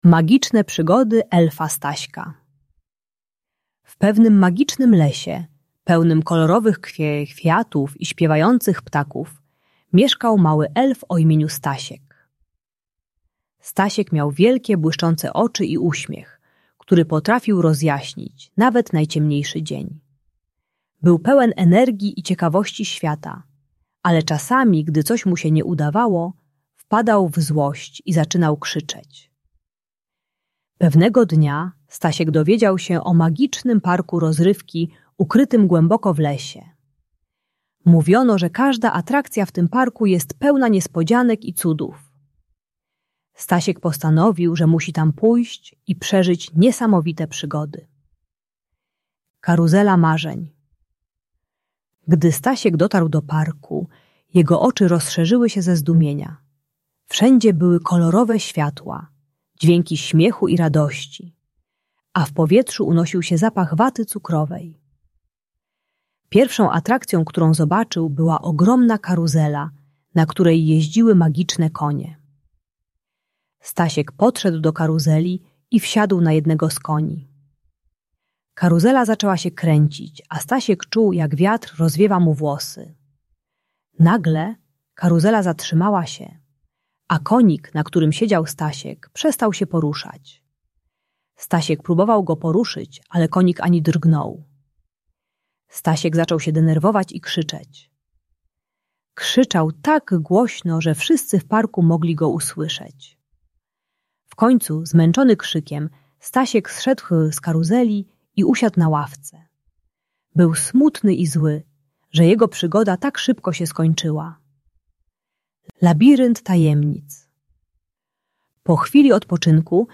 Elf Stasiek uczy się techniki głębokiego oddechu i zatrzymania się do przemyślenia zamiast krzyku i płaczu. Audiobajka o radzeniu sobie z frustracją i buntem u małych dzieci.